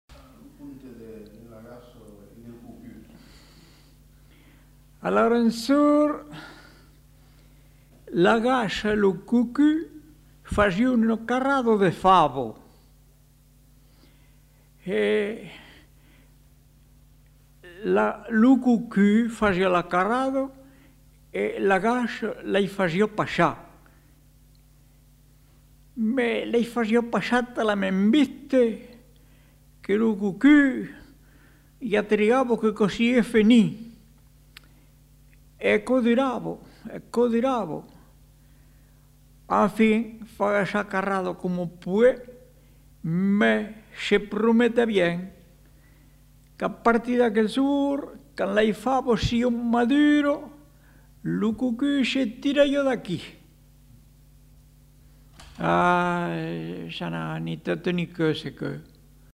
Aire culturelle : Périgord
Lieu : Lolme
Genre : conte-légende-récit
Effectif : 1
Type de voix : voix d'homme
Production du son : parlé
Contextualisation de l'item : récit étiologique